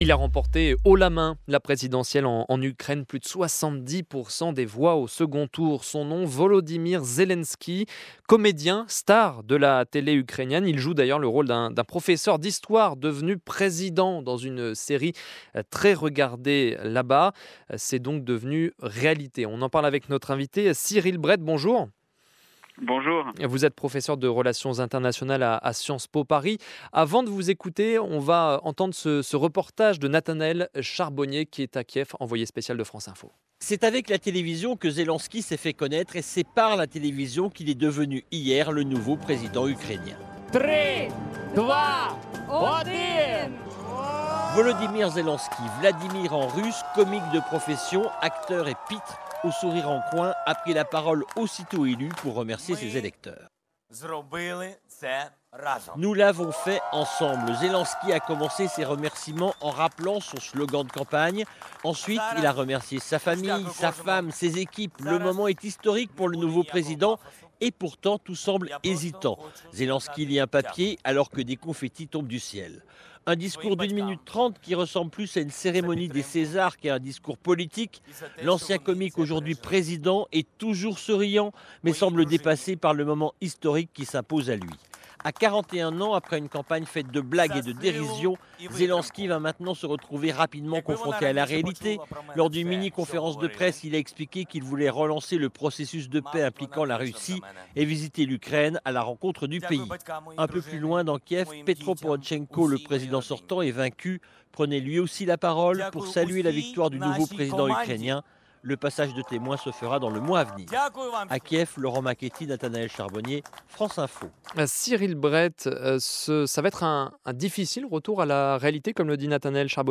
France Info interroge